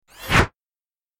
دانلود آهنگ باد 62 از افکت صوتی طبیعت و محیط
دانلود صدای باد 62 از ساعد نیوز با لینک مستقیم و کیفیت بالا
جلوه های صوتی